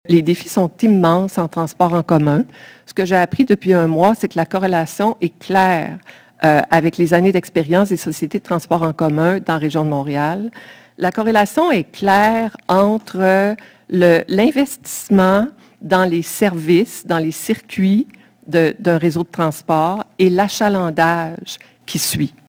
La mairesse de Saint-Lambert Pascale Mongrain a laissé savoir lors de la séance du conseil municipal d’avril qu’il pourrait y avoir de possibles coupures de services au Réseau de transport de Longueuil (RTL).